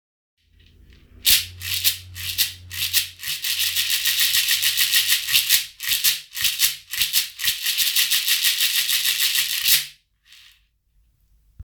種シェケレ(シェイカー) L
ひょうたんに種をつけたシェケレです。ビーズタイプより音がきつくなく素朴で抜けのよい音色が特徴。
素材： ひょうたん 実 木綿糸